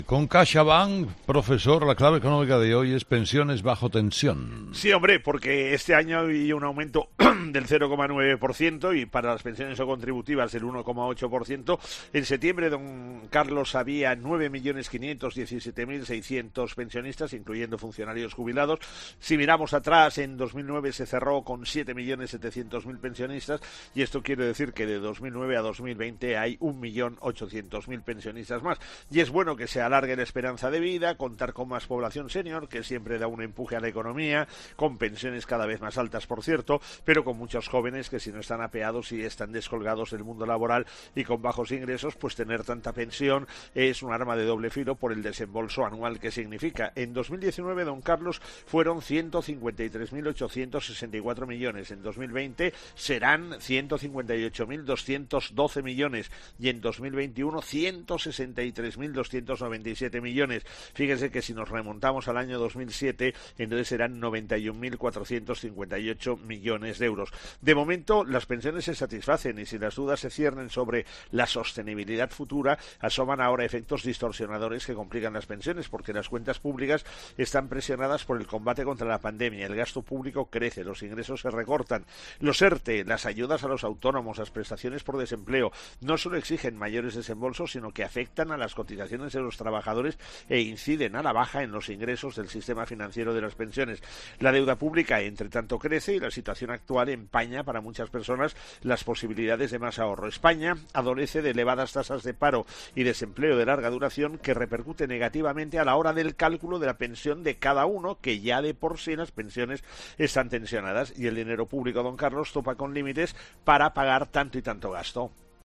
El profesor José María Gay de Liébana analiza en'Herrera en COPE’ las claves económicas del día.